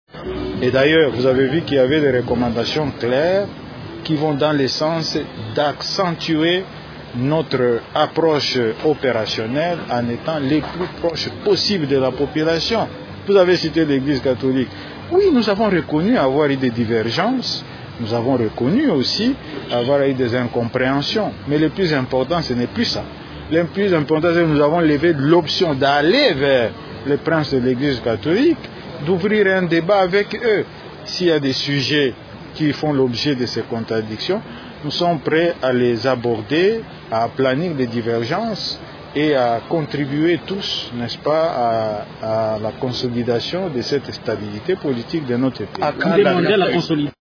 Vous pouvez suivre Néhémie Mwilanya ici :